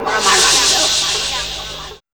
2806R VOX-FX.wav